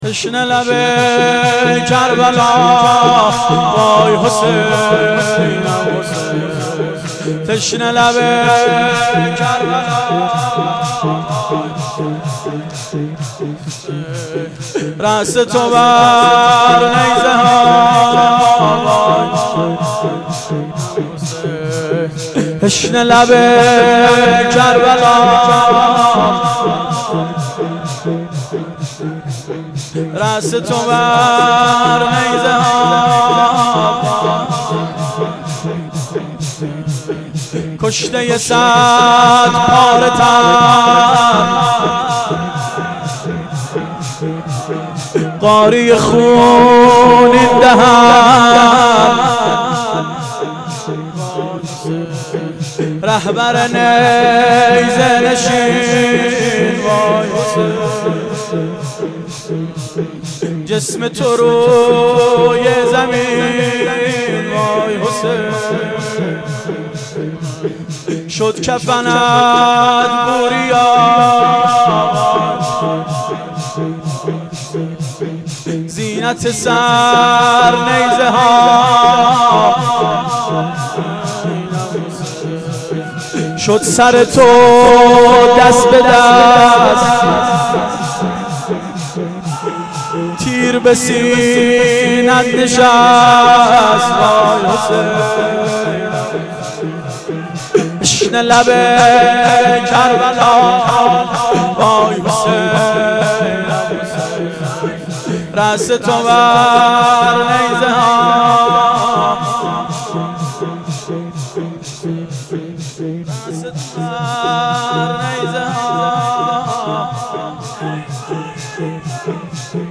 شور شب عاشورا98